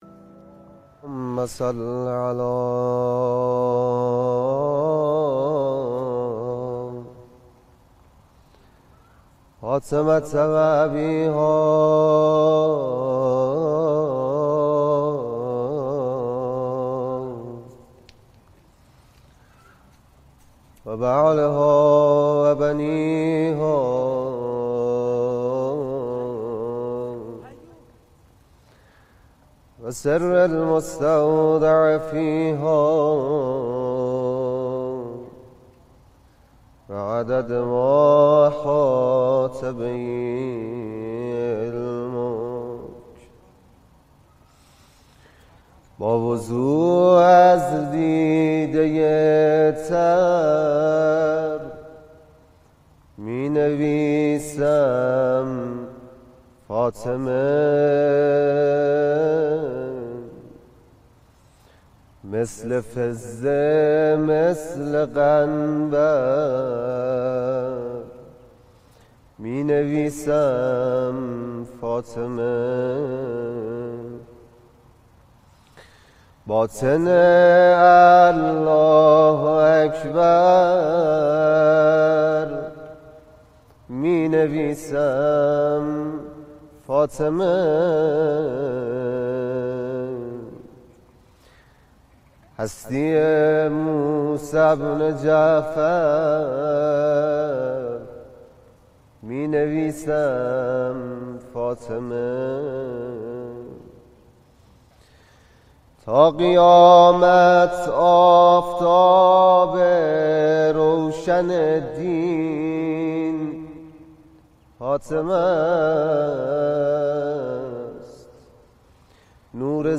مداحی اهل بیت ، روضه خوانی